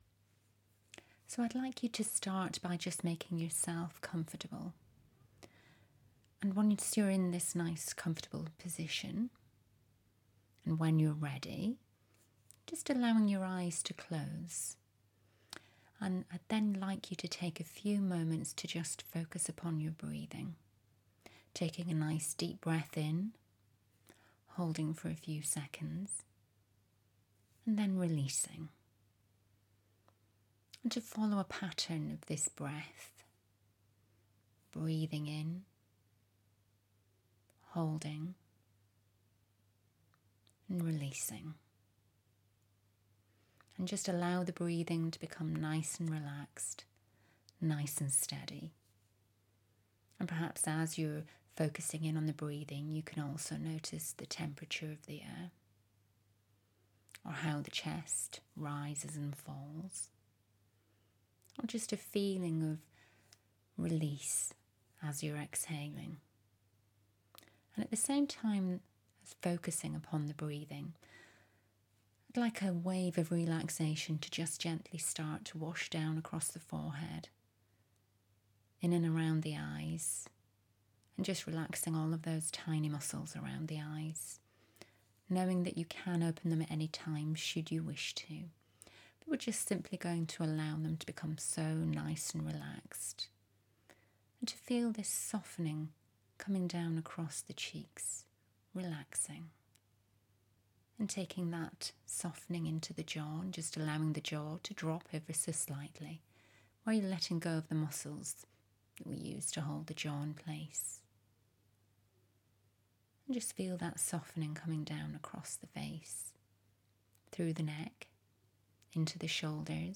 AUDIO relaxation session